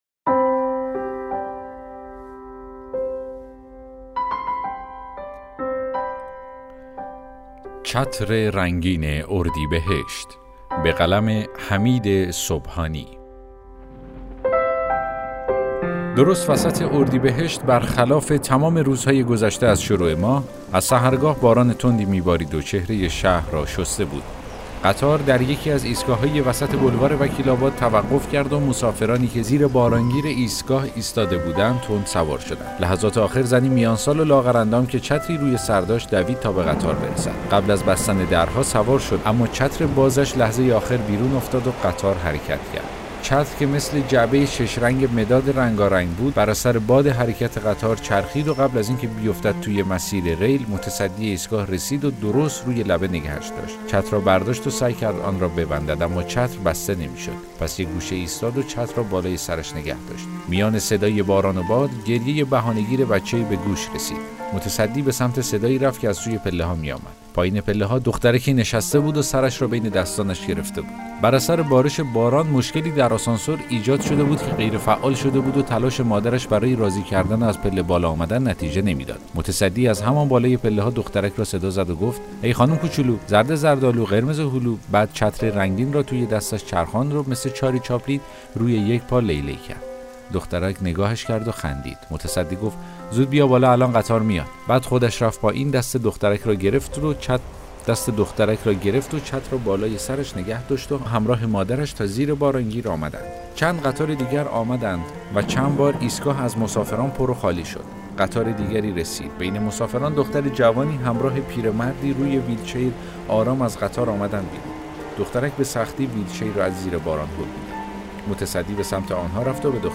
داستان صوتی: چتر رنگین اردیبهشت